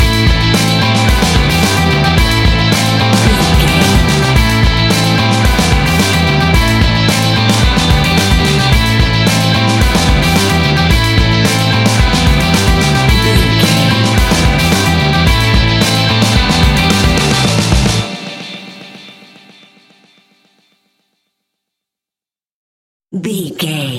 Ionian/Major
Fast
energetic
driving
happy
bright
electric guitar
bass guitar
drums
hard rock
distortion
instrumentals
heavy drums
distorted guitars
hammond organ